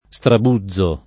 strabuzzo [ S trab 2zz o ]